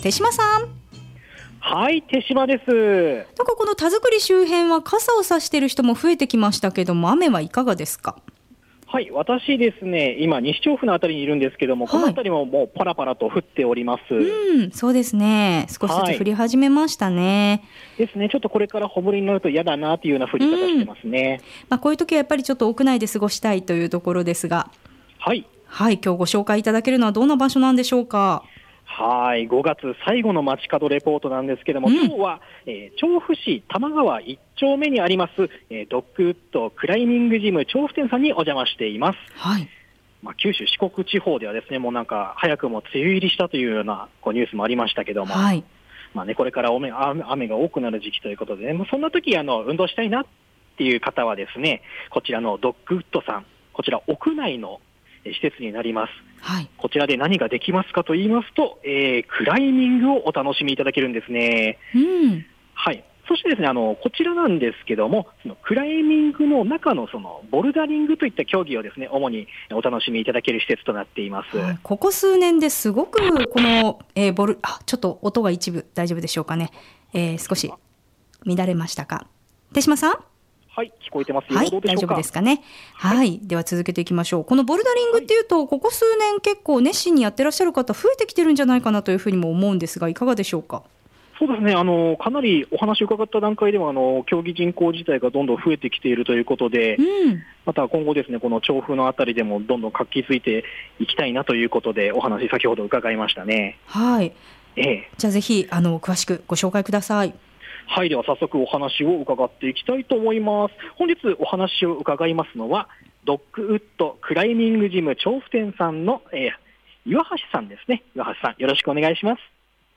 街角レポートのお時間は、調布周辺は若干の小雨が降っていました～。